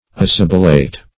Assibilate \As*sib"i*late\, v. t. [L. assibilatus, p. p. of